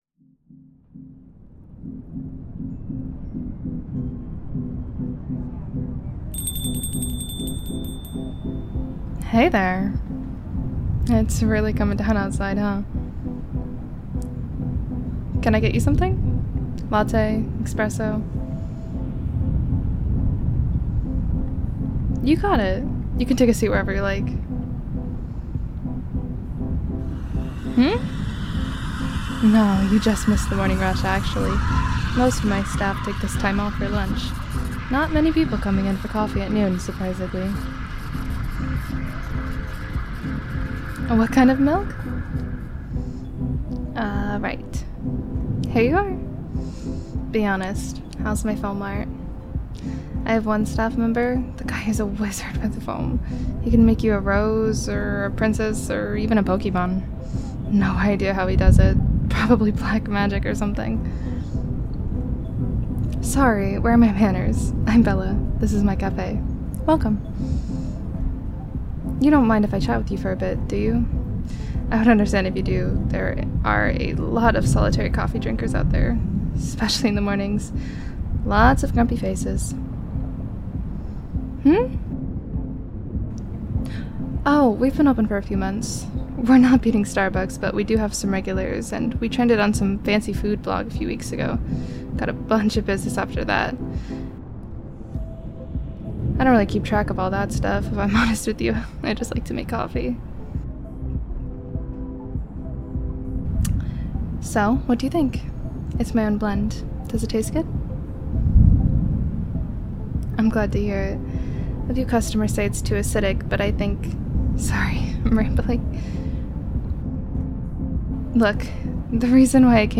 Barista Comforts You On A Rainy Day 🌧 [Rain] [Bonding] [Comfort]